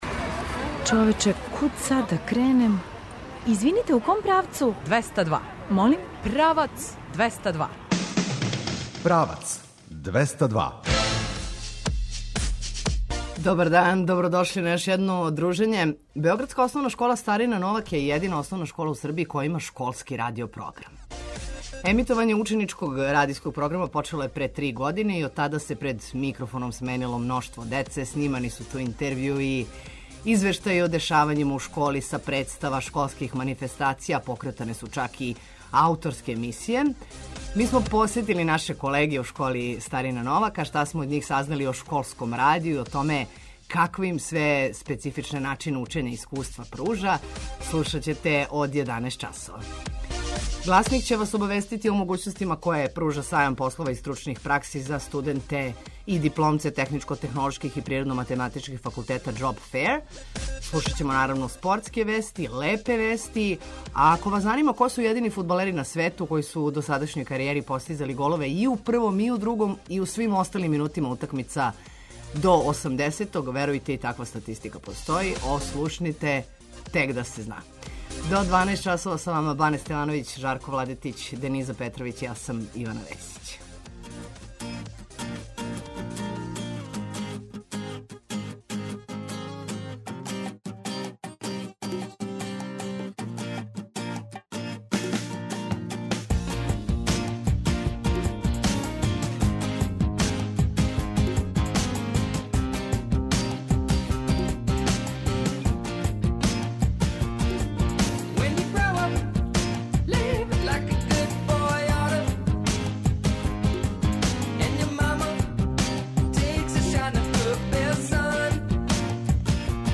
Гласник ће вас обавестити о могућностима које пружа Сајам послова и стручних пракси за студенте и дипломце техничко-технолошких и природно-математичких факултета „JobFair”, слушаћете спортске вести и лепе вести а ако вас занима ко су једини фудбалери на свету који су у досадашњој каријери постизали голове и у првом, и у другом и у свим осталим минутима утакмица до 90-ог ослушните Тек да се зна.